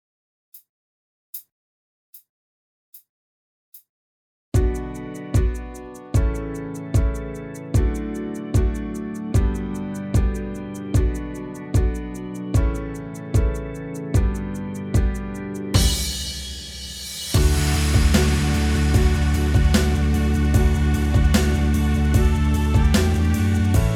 Minus All Guitars Pop (2010s) 4:21 Buy £1.50